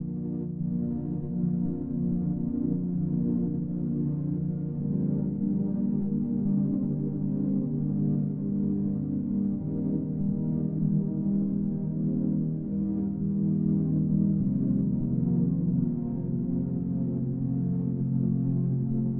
Reborn_A#Maj_130.wav